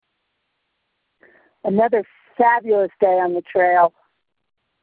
(Message cuts off)